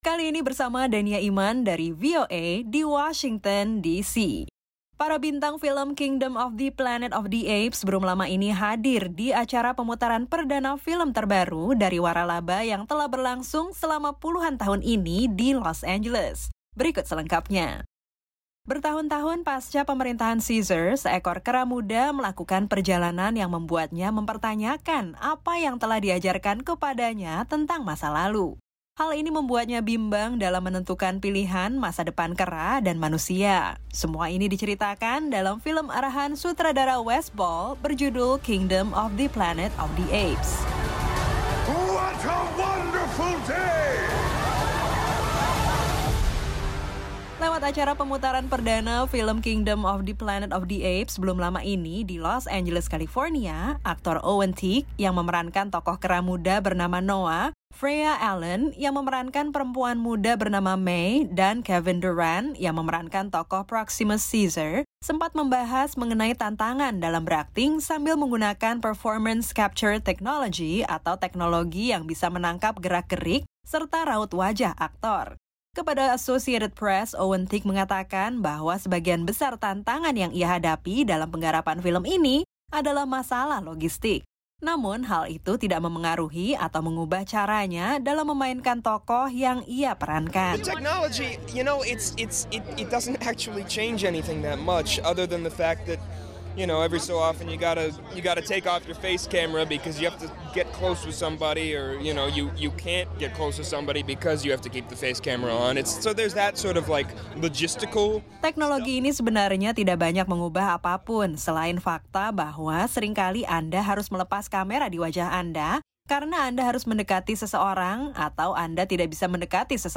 Lewat acara pemutaran perdana film “Kingdom of the Planet of the Apes” belum lama ini di Los Angeles, aktor Owen Teague yang memerankan tokoh kera muda bernama Noa, Freya Allan, yang memerankan perempuan muda bernama Mae, dan Kevin Durand yang memerankan tokoh Proximus Caesar, sempat membahas mengenai tantangan dalam berakting dengan menggunakan performance capture technology atau teknologi yang bisa menangkap gerak-gerik, serta raut wajah aktor.